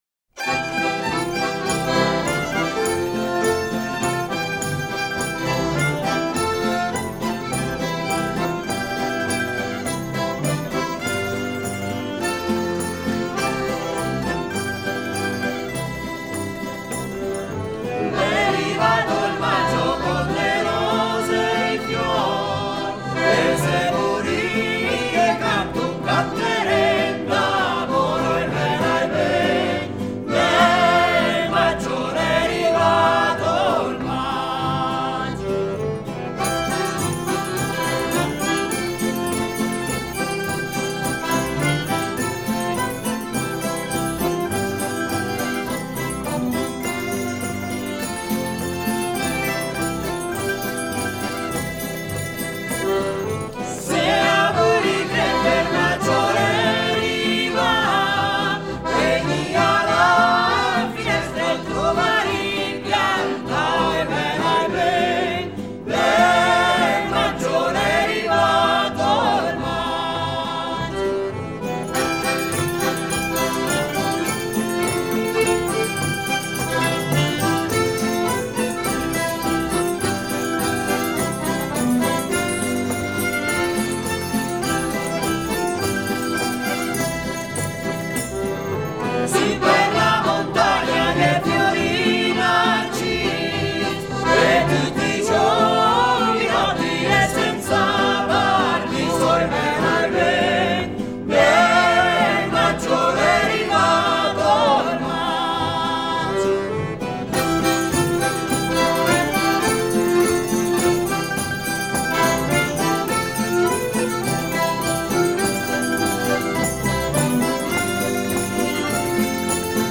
Ticino: Genuine Folk Music from Southern Switzerland
Vox Blenii